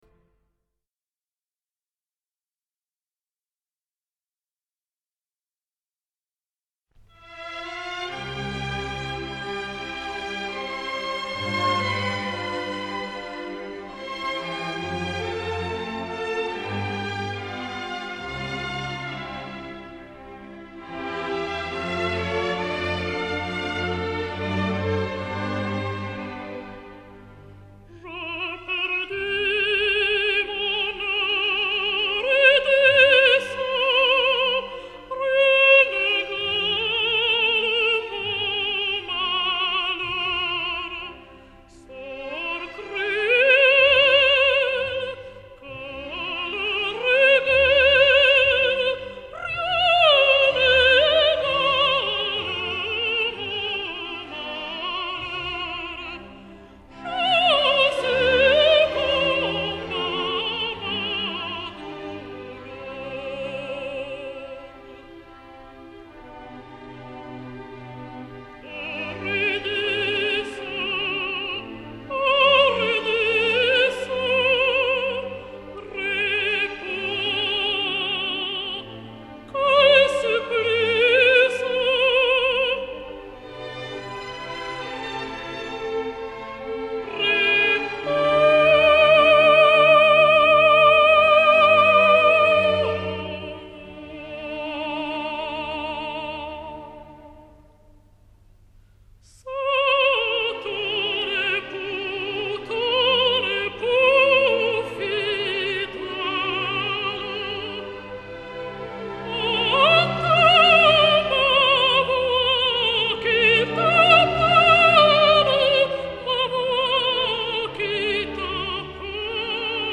Несколько записей прекрасной румынской певицы меццо-сопрано Елены Черней ( 1924-2000)